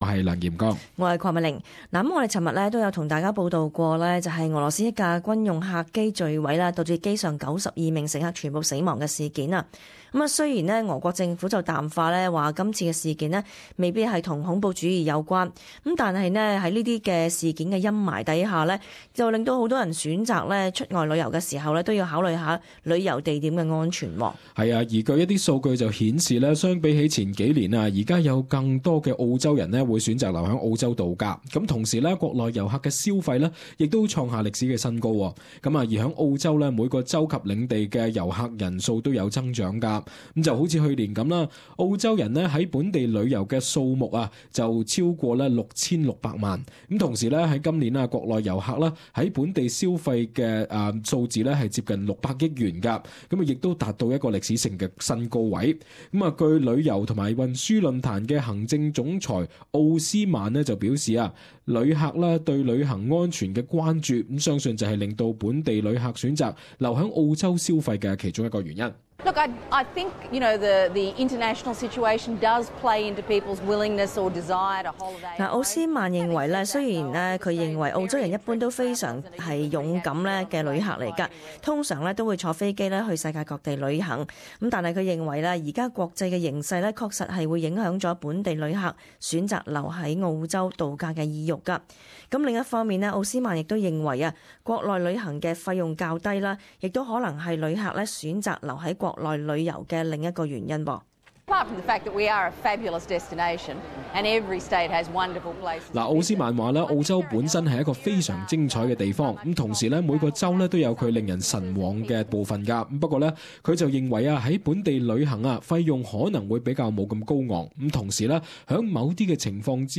【時事報導】 澳洲本地遊客數字達新高